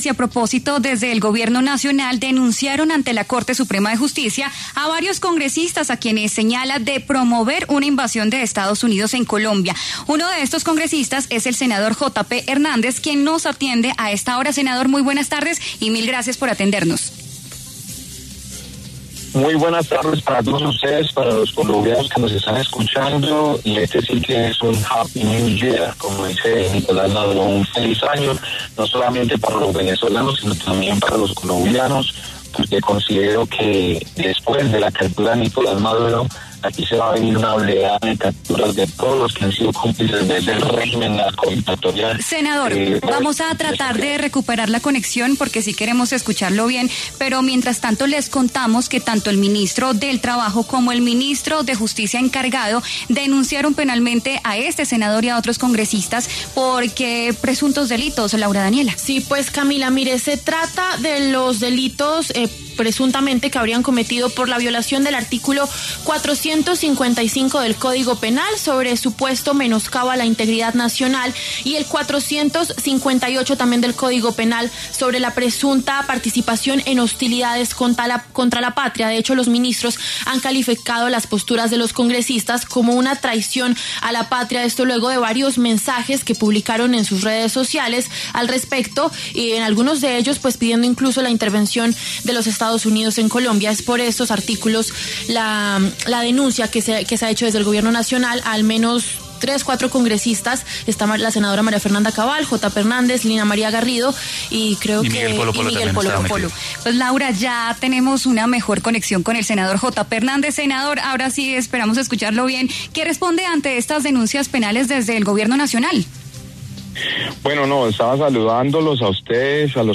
El senador Jota Pe Hernández habló en La W de las denuncias presentadas por el Gobierno ante la Corte Suprema de Justicia contra varios congresistas, por presuntamente promover una intervención militar de Estados Unidos en Colombia.
Tras las denuncias presentadas por el Gobierno ante la Corte Suprema de Justicia contra varios congresistas, por presuntamente promover una intervención militar de Estados Unidos en Colombia, el senador Jota Pe Hernández, de la Alianza Verde y uno de los denunciados, pasó por los micrófonos de La W.